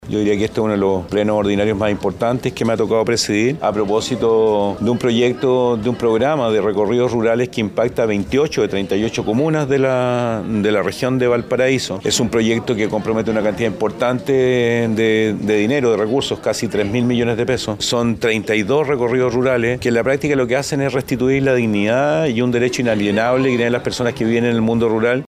Tras esta aprobación, el gobernador de Valparaíso, Rodrigo Mundaca, indicó que es uno de los plenos más importantes que le ha tocado presidir.